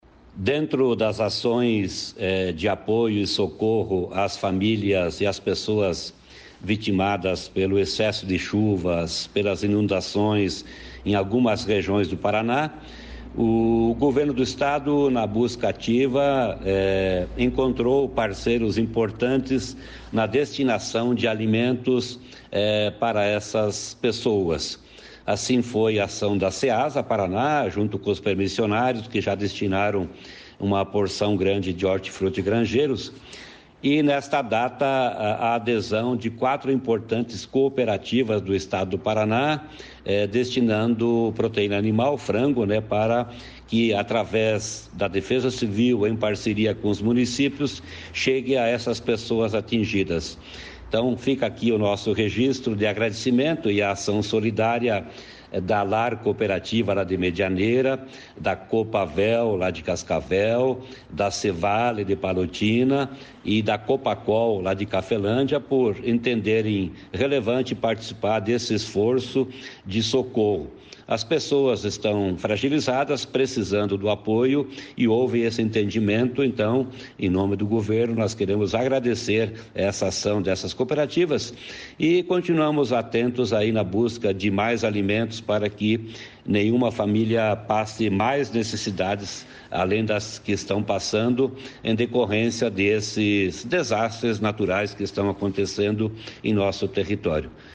Sonora do secretário de Agricultura e Abastecimento, Norberto Ortigara, sobre as medidas adotadas pelo Estado para auxílio aos municípios afetados pelas fortes chuvas no Paraná